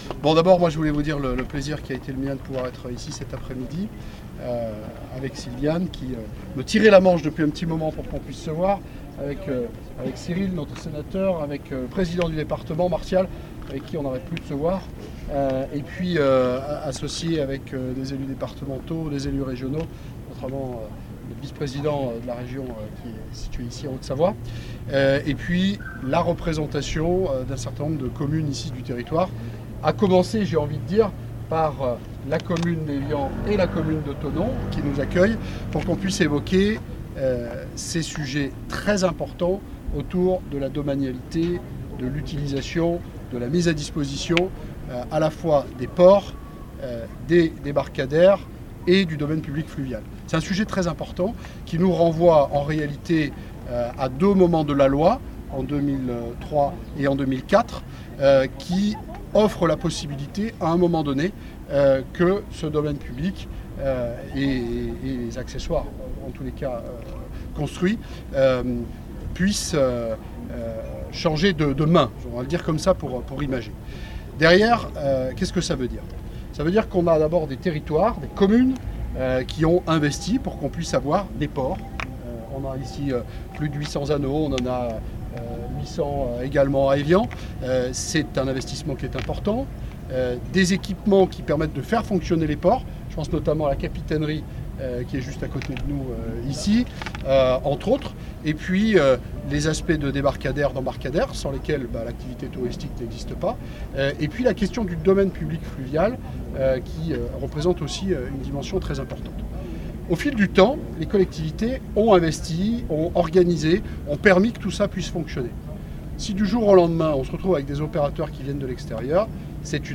Concession des Rives du Léman: La région ne prendra pas la main (interview)